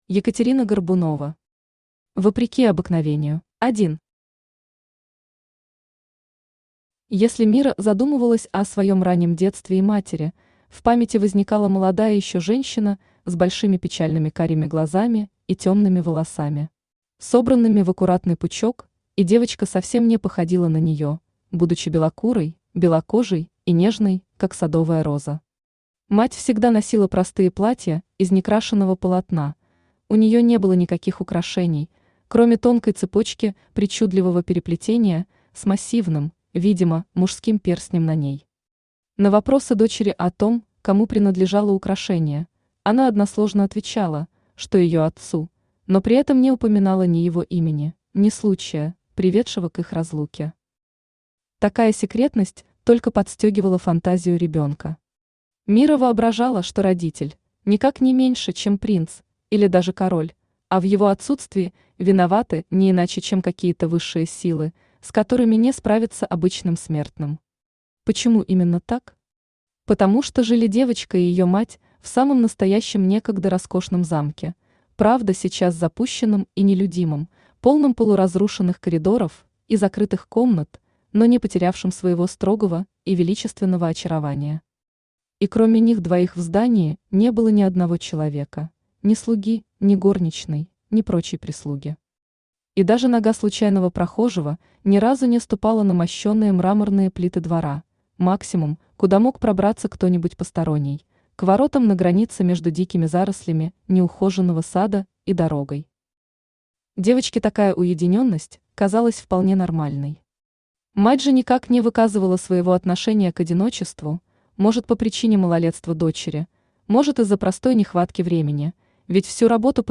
Аудиокнига Вопреки обыкновению | Библиотека аудиокниг
Aудиокнига Вопреки обыкновению Автор Екатерина Анатольевна Горбунова Читает аудиокнигу Авточтец ЛитРес.